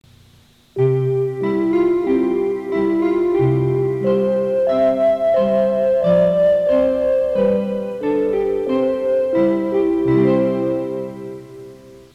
２３０００系「伊勢志摩ライナー」と、２１０００系・２１０２０系「アーバンライナー」の自動放送では“各駅到着前始発駅発車前に違う車内チャイム”が流れます。